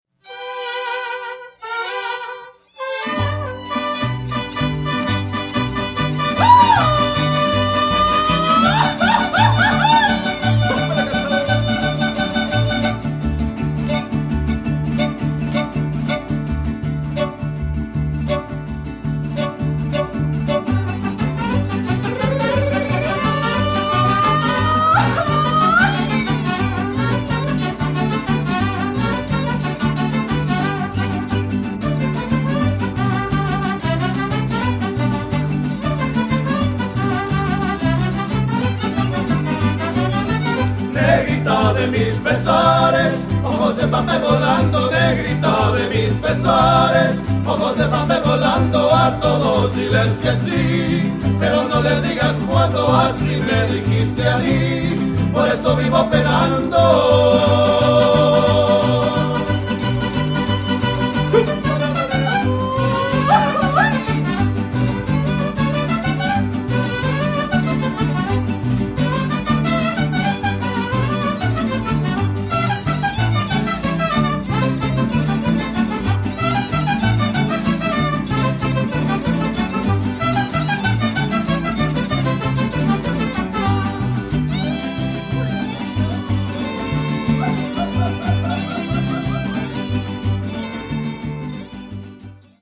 This Salt Lake City Mariachi is one of the most exciting mariachi groups in the Northwestern United States, playing contemporary and traditional mariachi music for over 25 years! This unique, fun and energetic group will make your event memorable and fun, a real fiesta!
Their style and musicianship allow for spontaneous and exciting sounds in every project or performance.